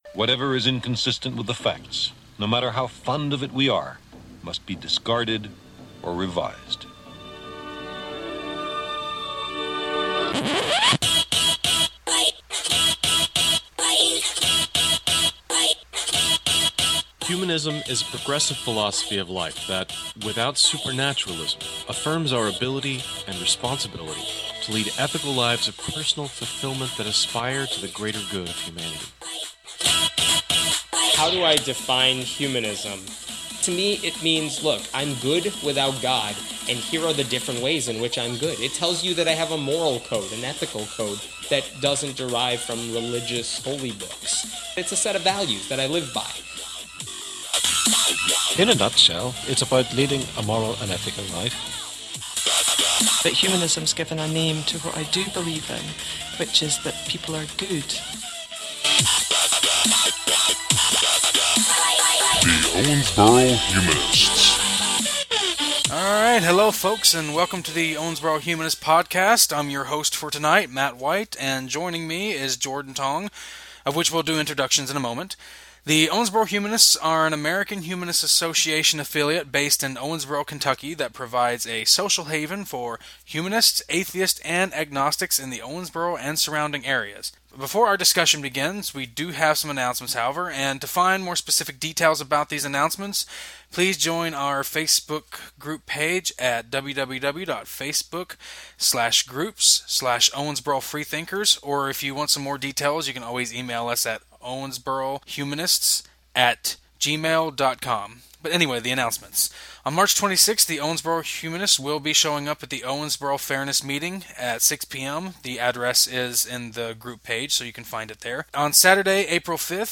The debate was not really a formal debate, but more of a discussion or dialogue. We each opened by giving our story of why we believe (or disbelieve) and then we each posed three questions to the other, challenging their worldview or some aspect of it.
Also, keep in mind as you listen that the original audio was much longer and some editing was done to cut out dead space and some rambling that happened. So if it sounds choppy at points, you know why.